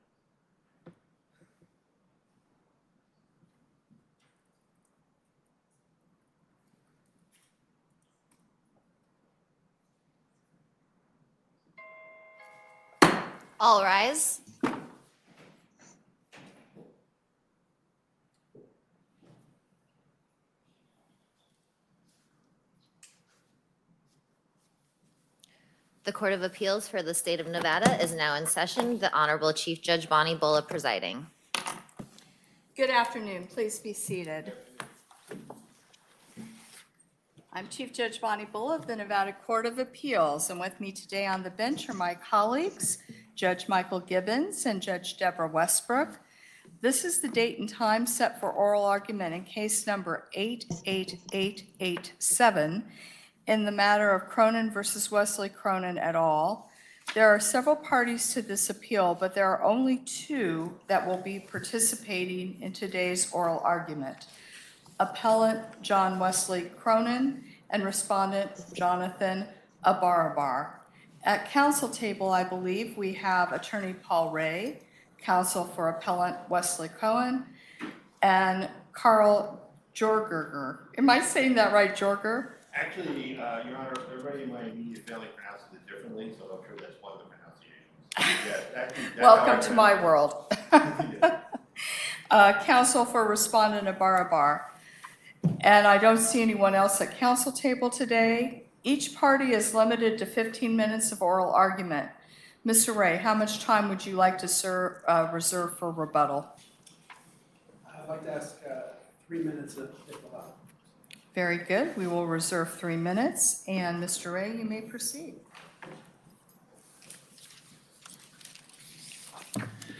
Before the Court of Appeals, Chief Judge Bulla presiding Appearances